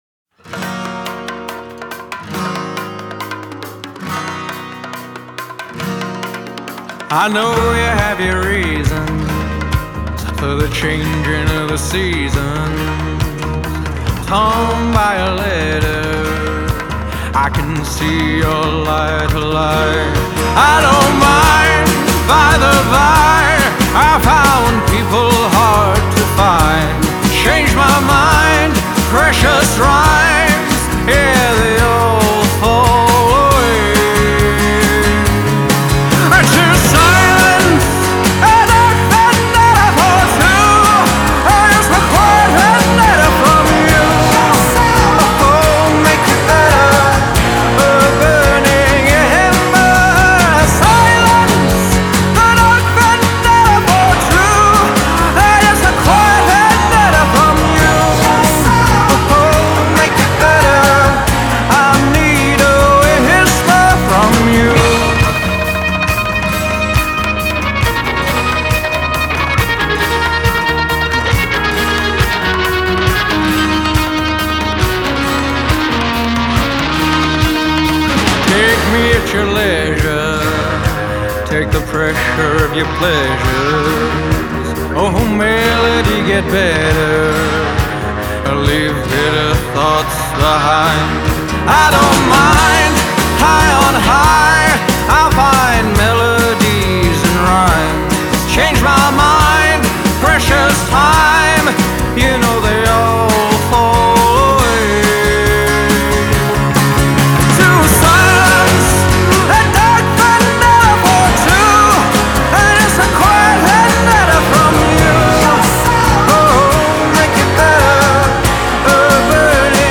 punk rock Roy Orbison vocals